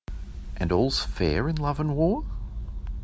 australia_1.wav